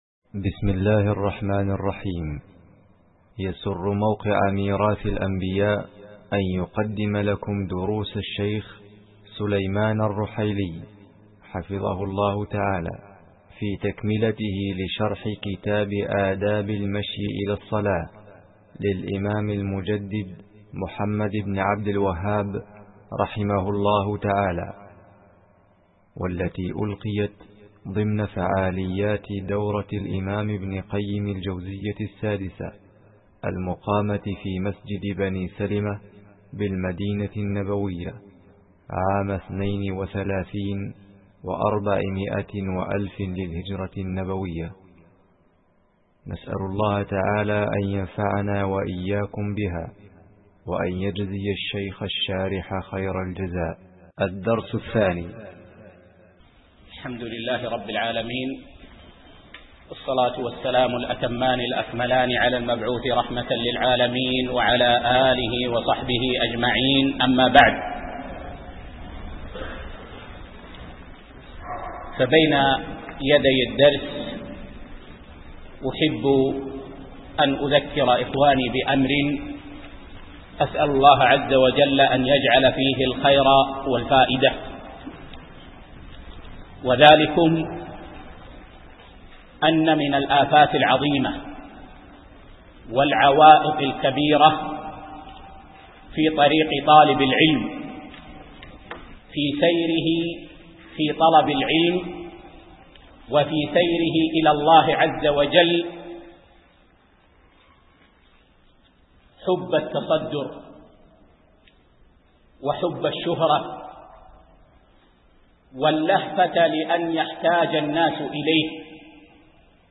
الدرس التاسع